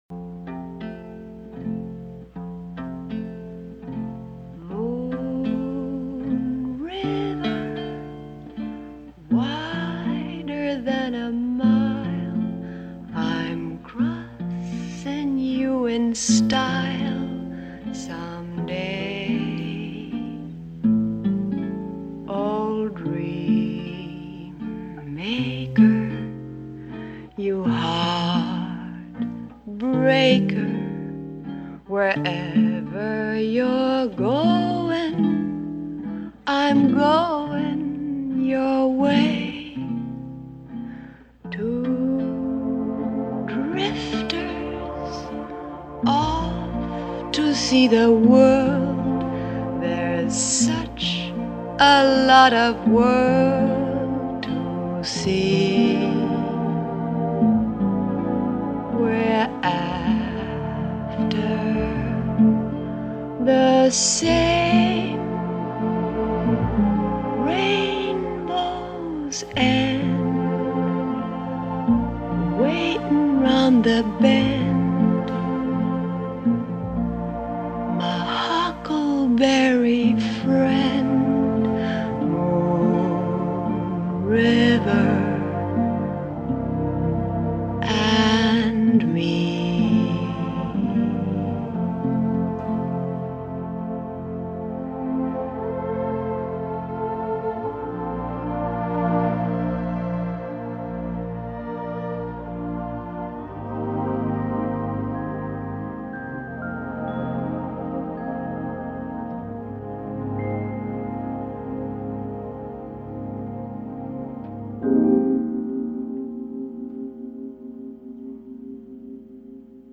电影插曲
昨天本想上传这个音质较好没有杂音的版本，因上传已满，所以今天传上，难得的珍蒧版本 本地下载160K MP3)